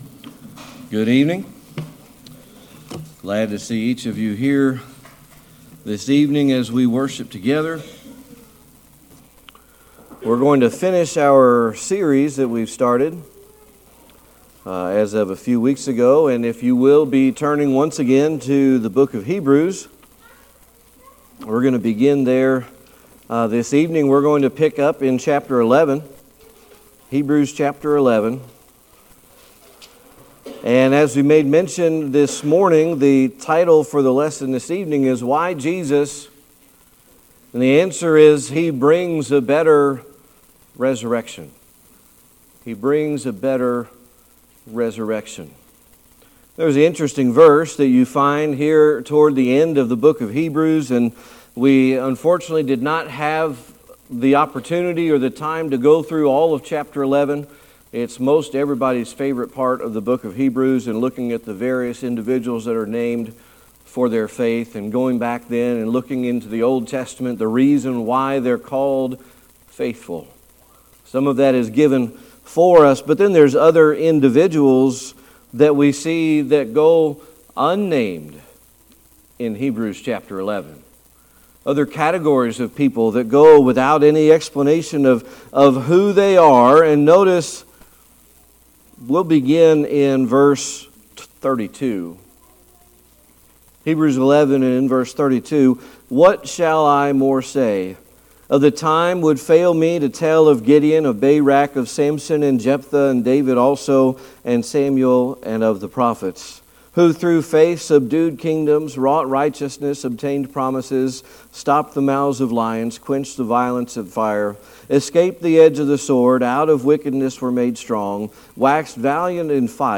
Why Jesus Service Type: Sunday Evening Worship We're going to finish our series that we've started as of a few weeks ago.